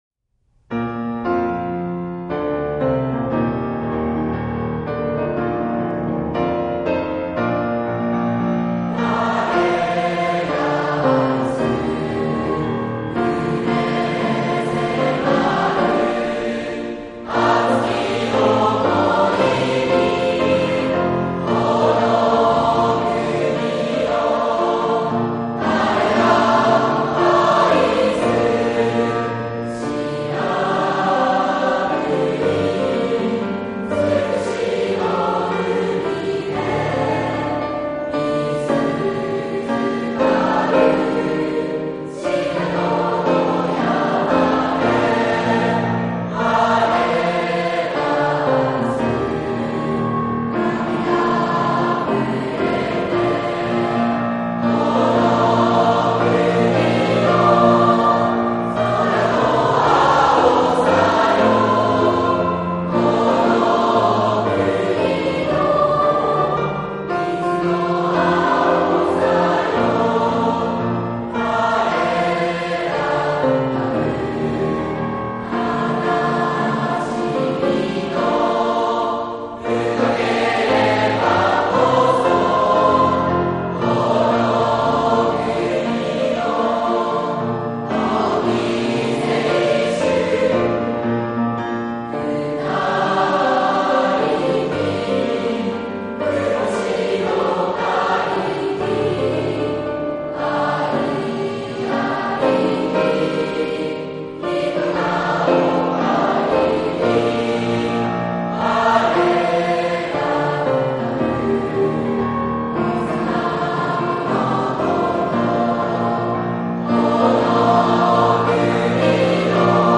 （歌：平成16年度卒業生）